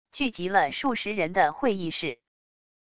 聚集了数十人的会议室wav音频